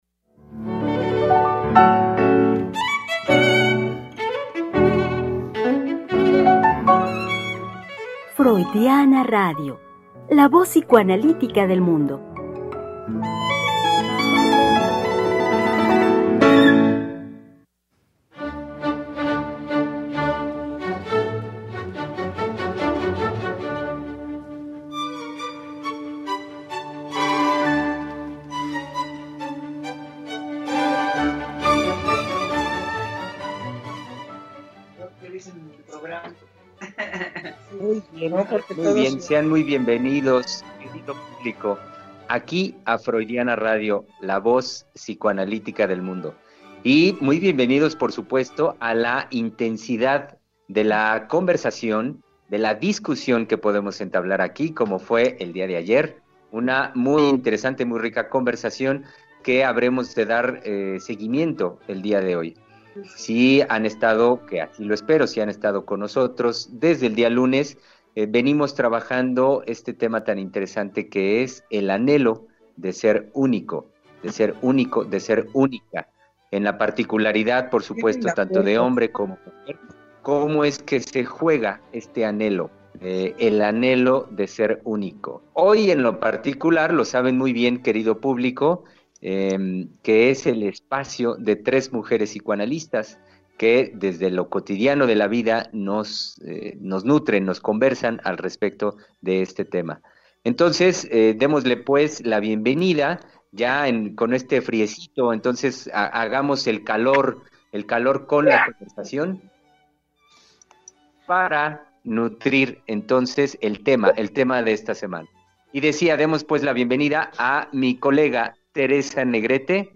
“El anhelo de ser único” 4ª Parte. Tres Mujeres Psicoanalistas Hablando de la Vida Cotidiana.
Conversación con las psicoanalistas